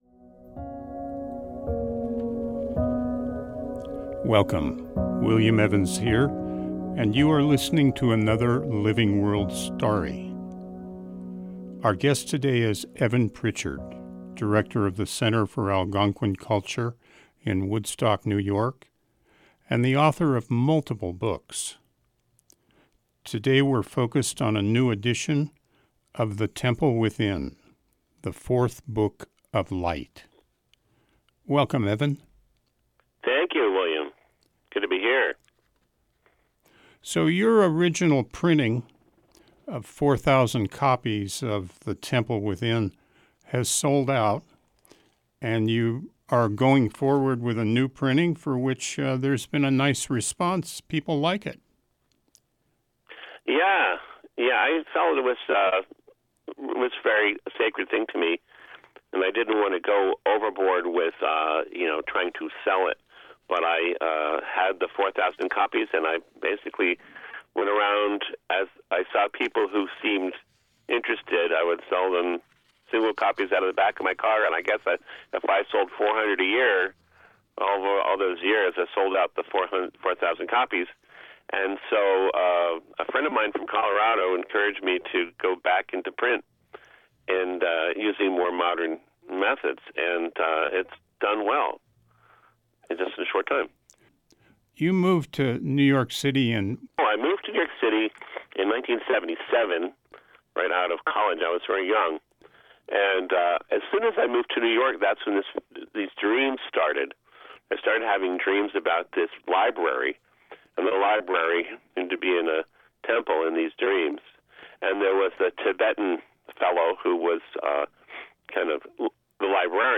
Shifting Gears features conversations with people making life-sustaining choices.